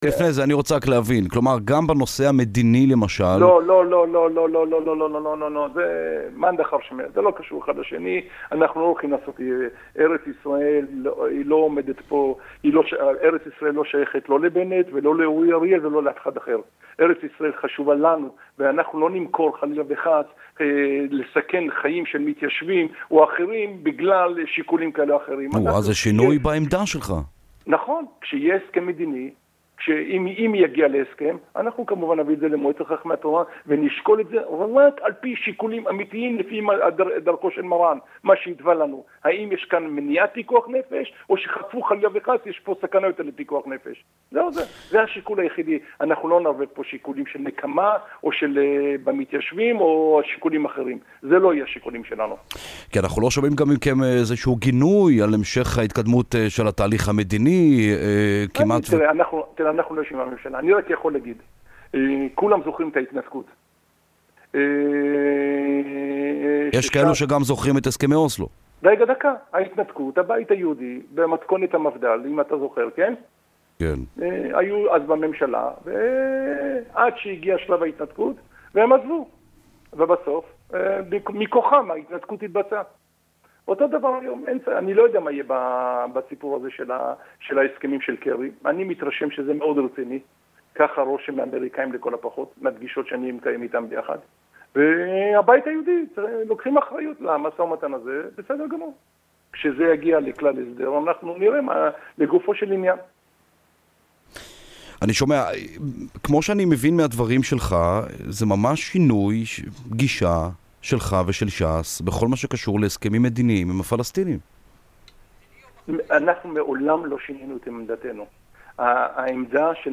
יו"ר ש"ס אריה דרעי התראיין היום (ה') בתחנת הרדיו החרדית קול ברמה והציג עמדה חדשה של ש"ס בנושא התהליך המדיני.
האזינו לדרעי מזגזג (באדיבות קול ברמה)